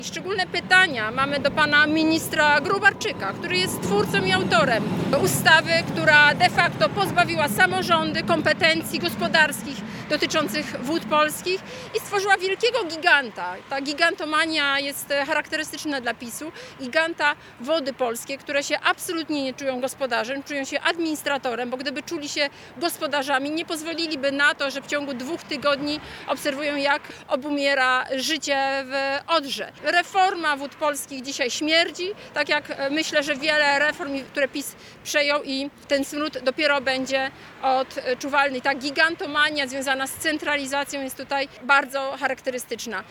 podczas dzisiejszej konferencji prasowej